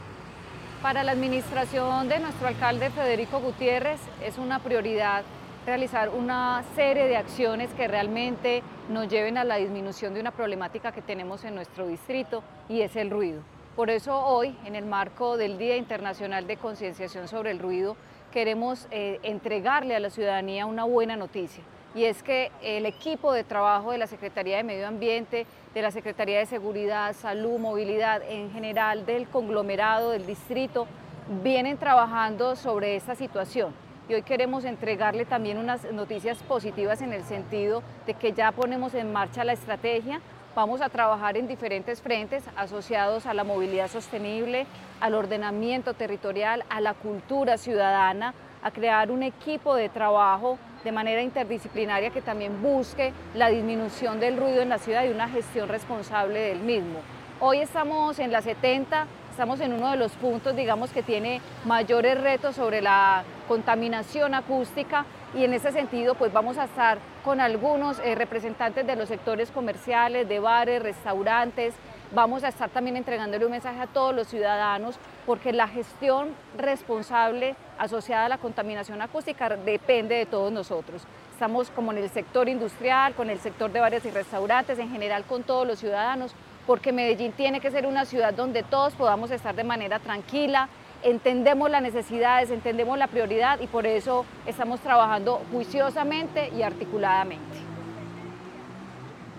Palabras de Ana Ligia Mora Martínez, secretaria de Medio Ambiente En el Día Internacional contra el Ruido, la Alcaldía de Medellín presentó el modelo de gestión integral para enfrentar la contaminación acústica en la ciudad.